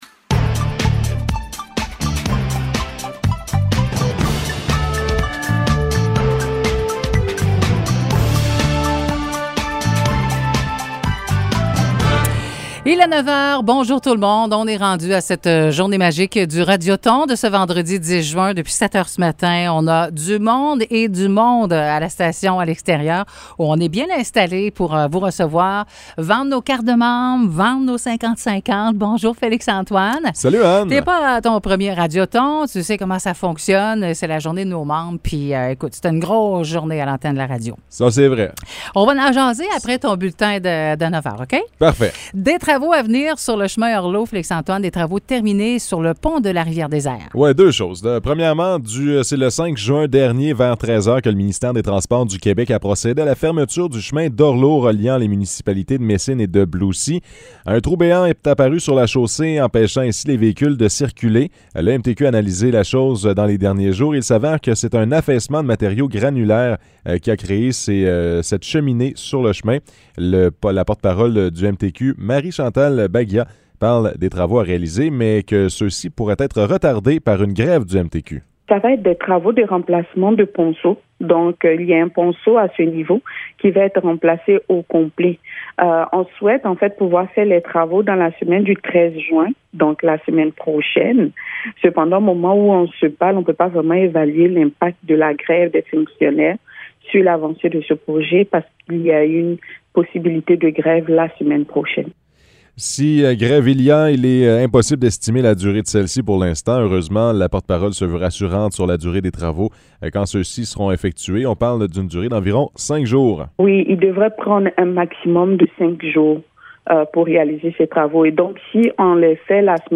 Nouvelles locales - 10 juin 2022 - 9 h